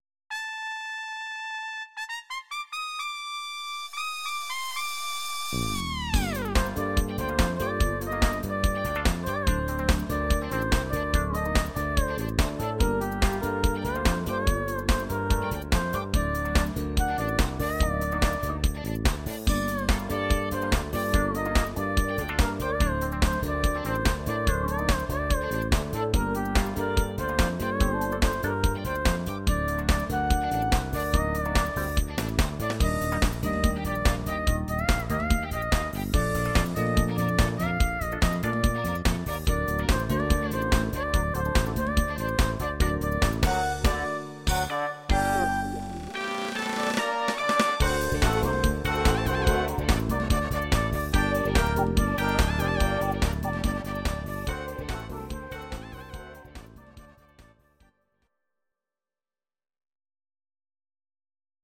Audio Recordings based on Midi-files
Pop, Musical/Film/TV, 2000s